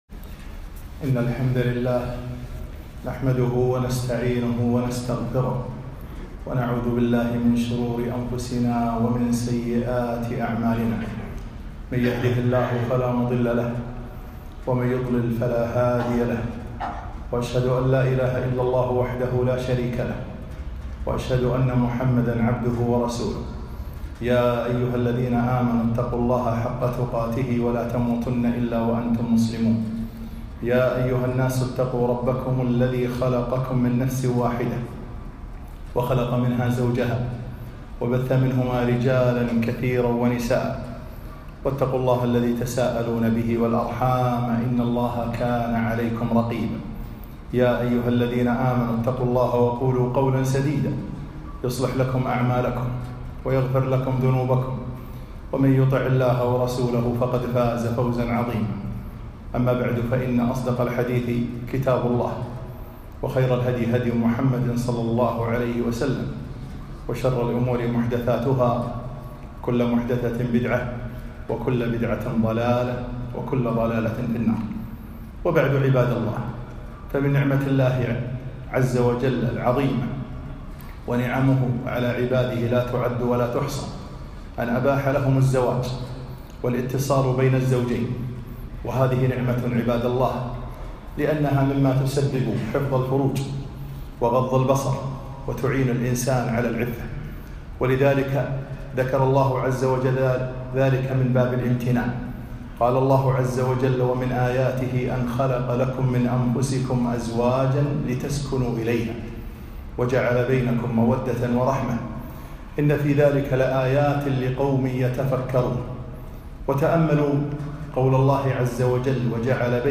خطبة - لا تستعجلوا الطلاق